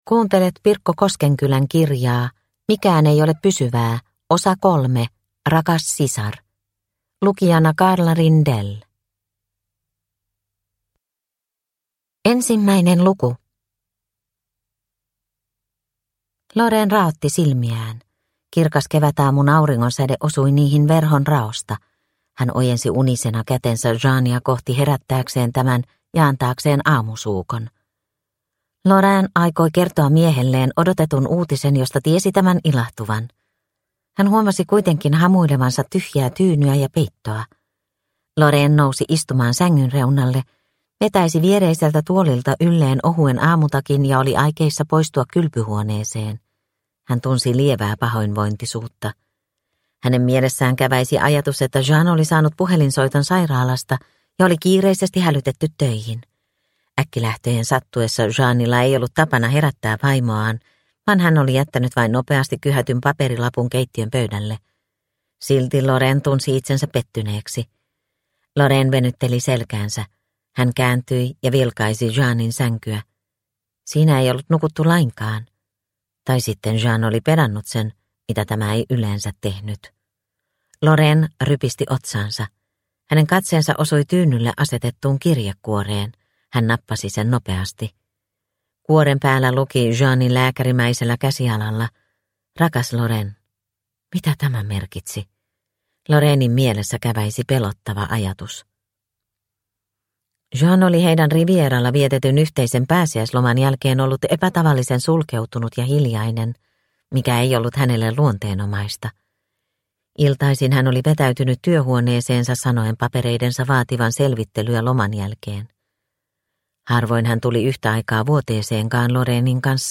Mikään ei ole pysyvää - Osa 3 Rakas sisar – Ljudbok – Laddas ner
Produkttyp: Digitala böcker